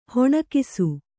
♪ hoṇakisu